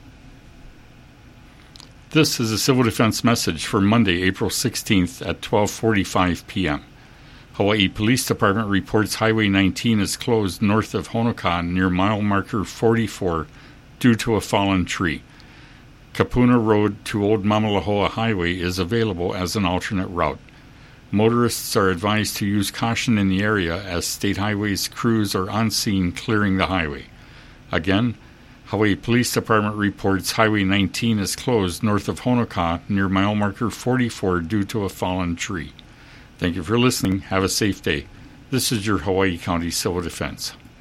Hawaii County Civil Defense 12:45 p.m. audio message